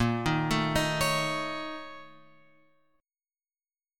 A# Minor Major 7th Double Flat 5th